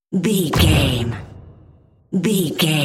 Dramatic hit slam door
Sound Effects
heavy
intense
dark
aggressive